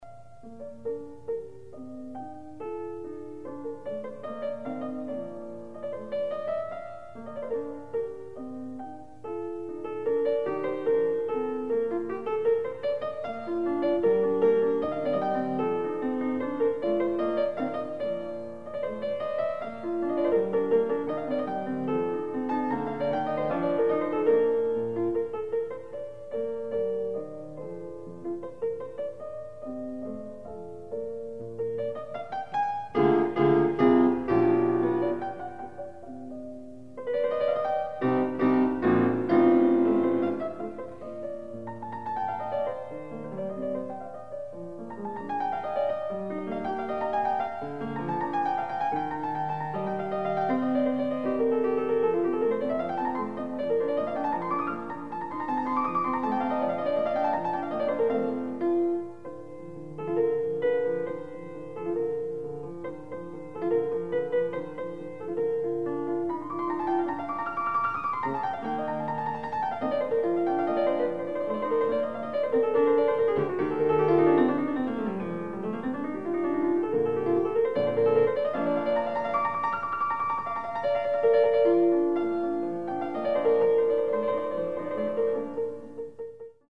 Additional Date(s)Recorded September 16, 1977 in the Ed Landreth Hall, Texas Christian University, Fort Worth, Texas
Short audio samples from performance